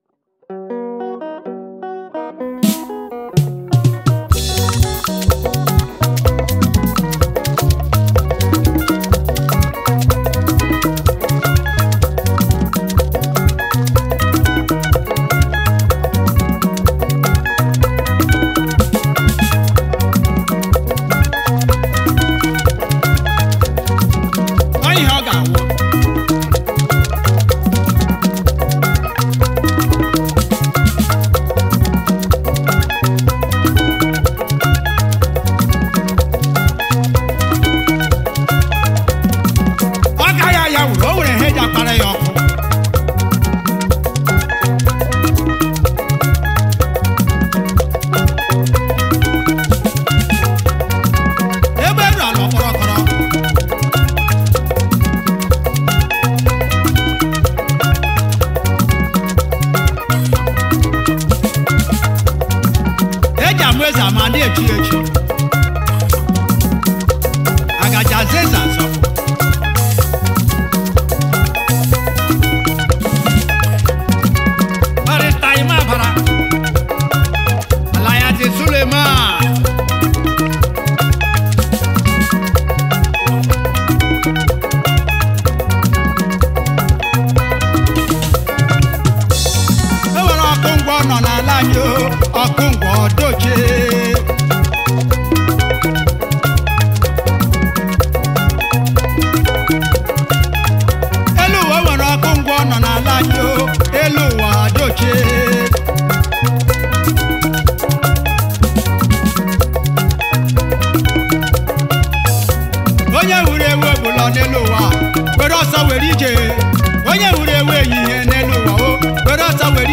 high life band